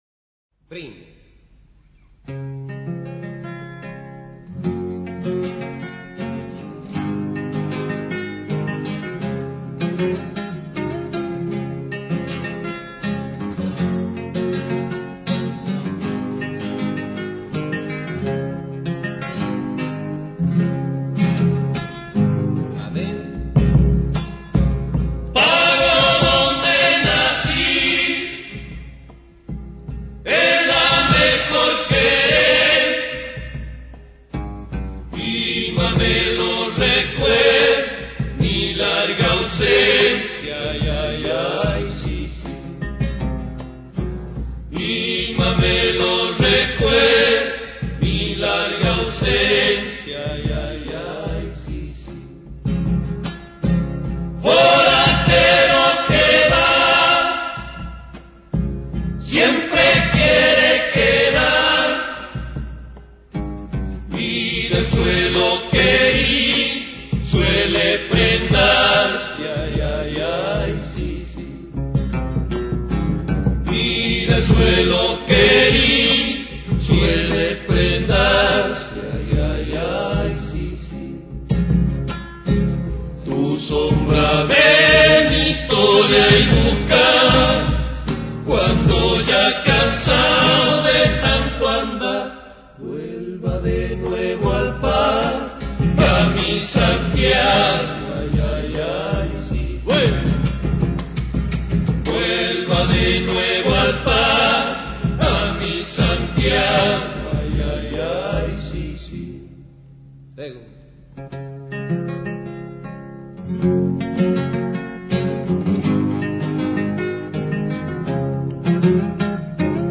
(Zamba)